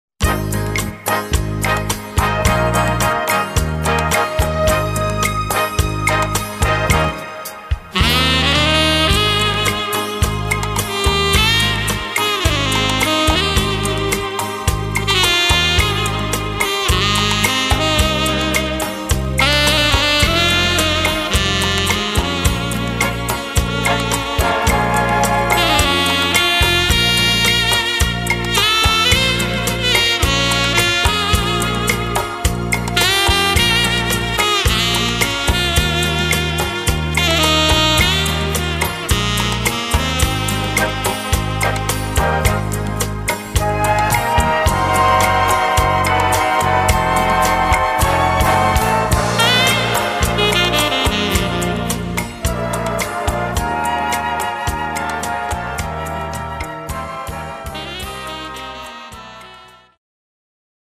Gattung: Solo für Tenorsaxophon
Besetzung: Blasorchester